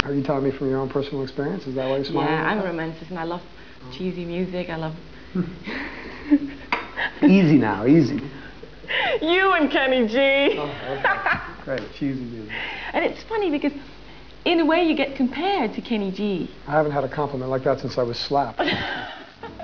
Sky Interview (212kb)